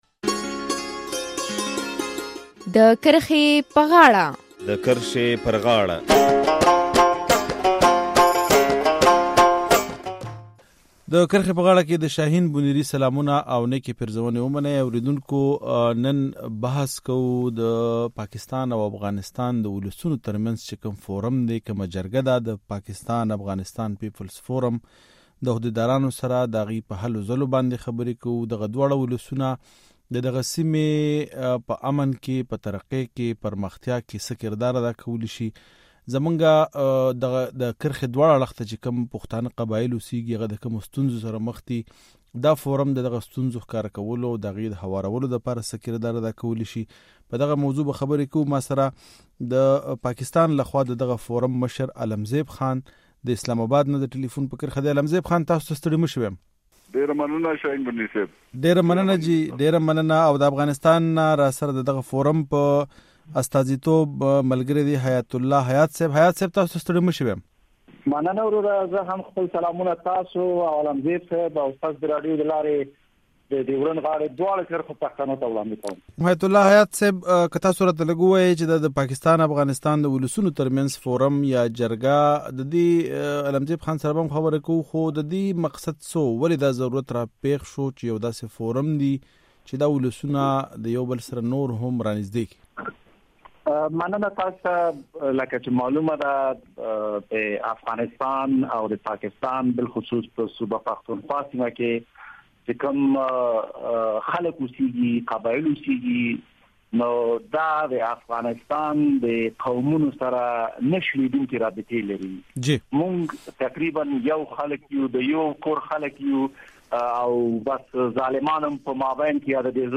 د کرښې پر غاړه خپرونه کې د پاکستان او افغانستان د ولسي فورم له مشرانو سره په دې موضوع خبرې کوو چې دغه فورم د سیمې د ولسونو د امنیت پر ځاي کولو کې څه کردار لوبولې شي؟